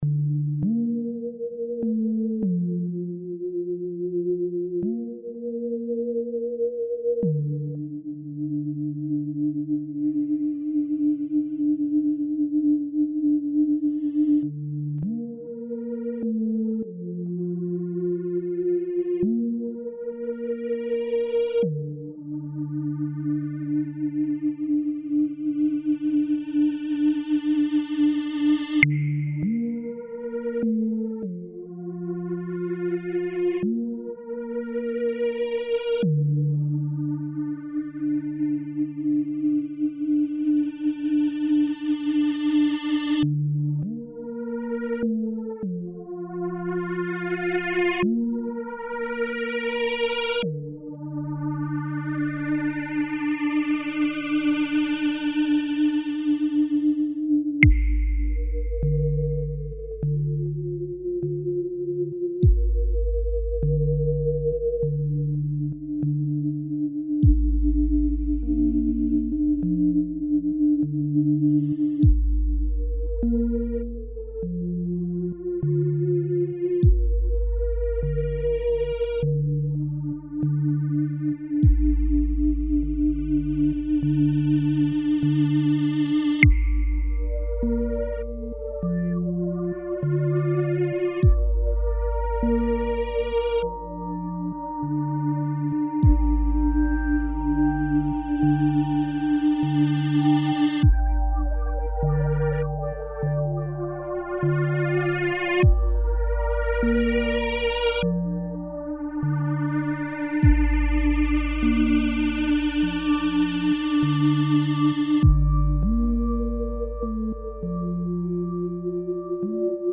Track ambient
Une ptite track ambient assez courte faite hier soir.
Faite gaffe, c'est trééés lent ....  Smiley ça met du temps a partir, si on peut appeler ça partir ... Smiley